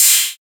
Closed Hats
SOUTHSIDE_hihat_crunk.wav